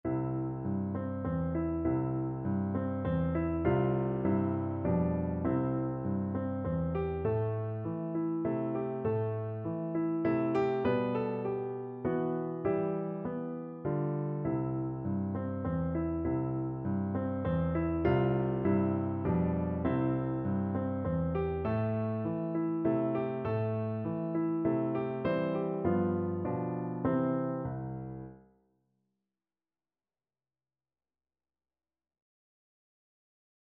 3/4 (View more 3/4 Music)
Moderato
Piano Duet  (View more Beginners Piano Duet Music)
Classical (View more Classical Piano Duet Music)